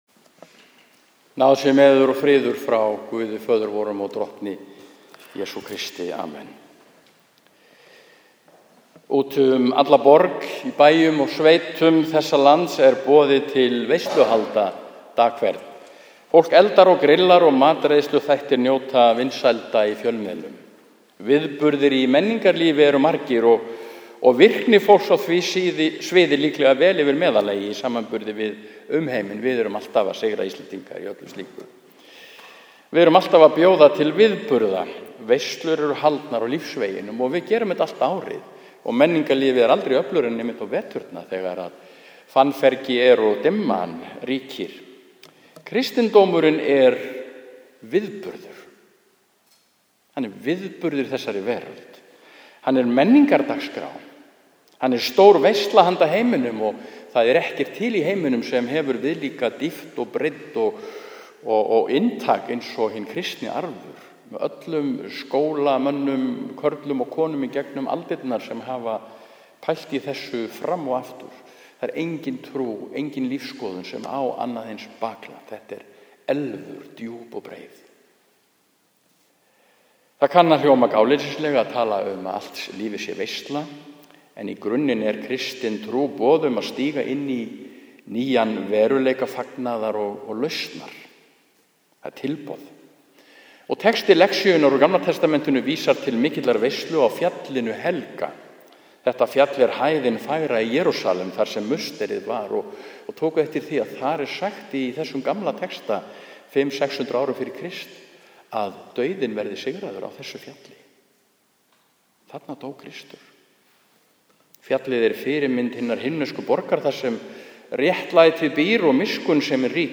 Prédikun við messu í Neskirkju sunnudaginn 29. júní 2014 – 2. sd. e. trinitatis